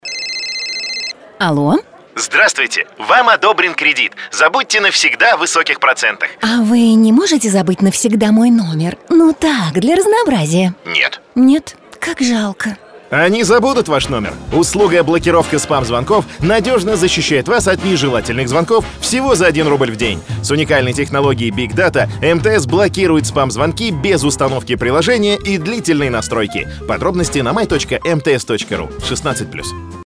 Жен, Рекламный ролик/Молодой